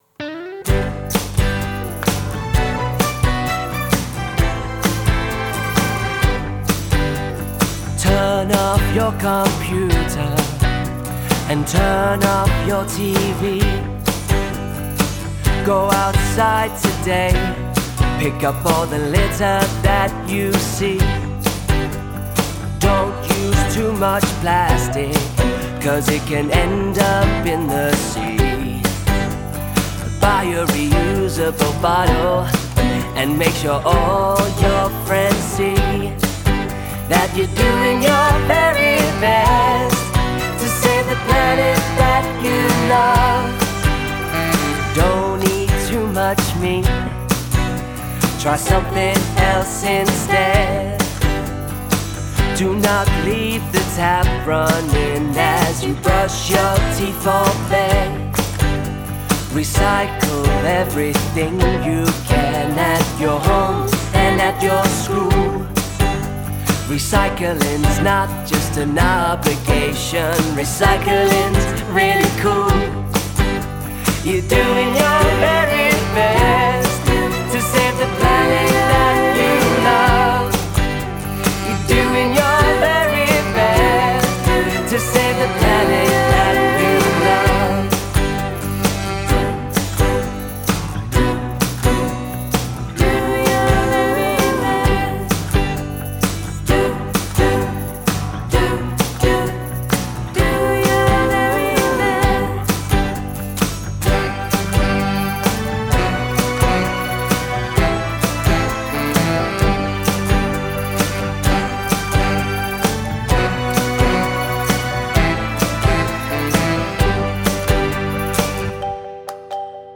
2. Songs